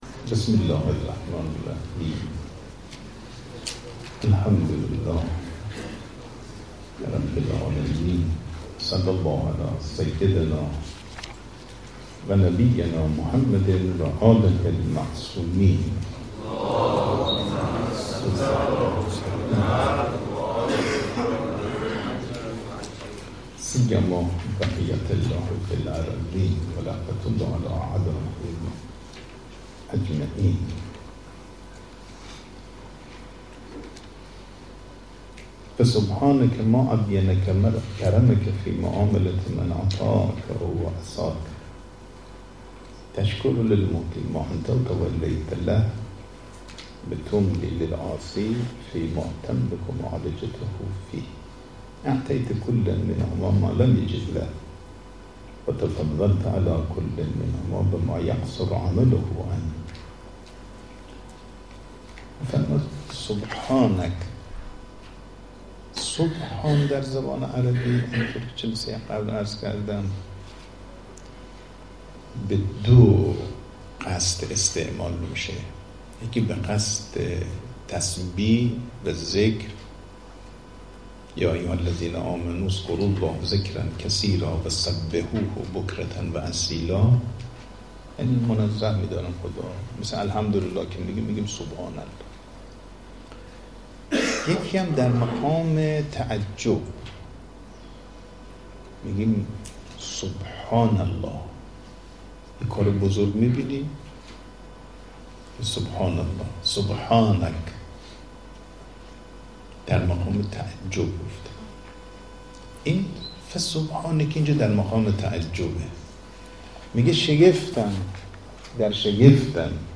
صوت | درس اخلاق نماینده ولی‌فقیه در بوشهر در مدرسه علمیه امام خمینی (ره)
حوزه/ درس اخلاق حجت‌الاسلام والمسلمین صفایی بوشهری در مدرسه علمیه امام خمینی (ره) بوشهر برگزار شد.